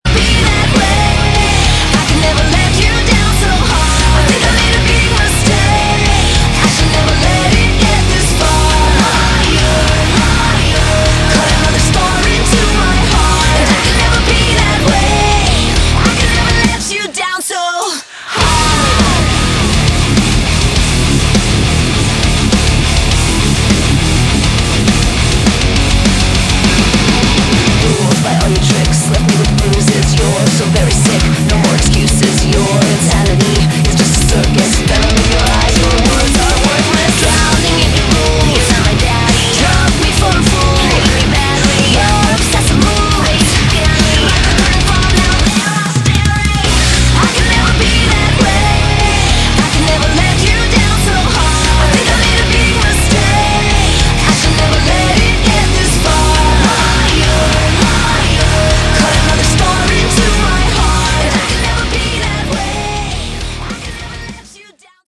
Category: Modern Hard Rock
guitars
drums